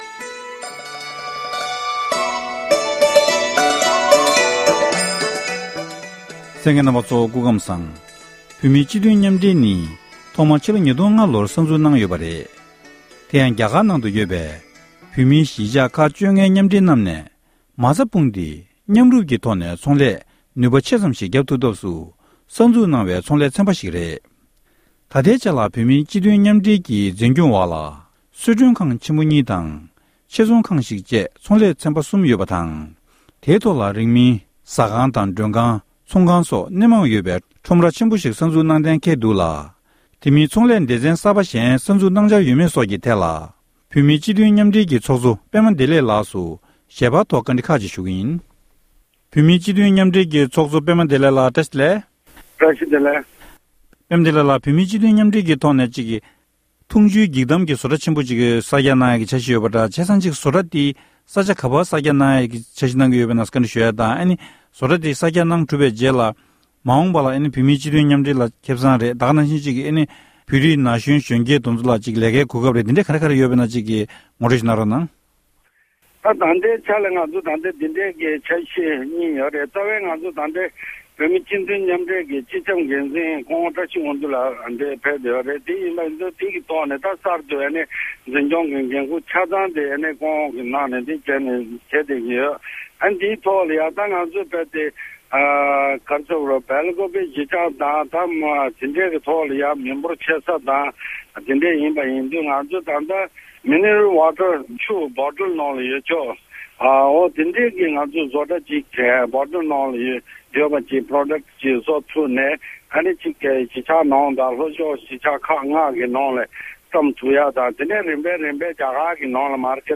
གནས་འདྲི་ཞུས་ཡོད༎